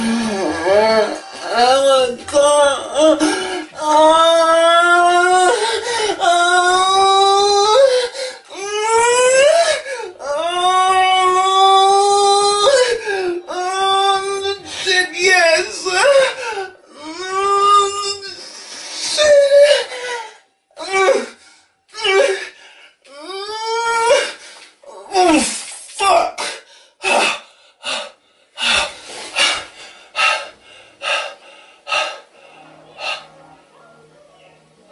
Speaking Alien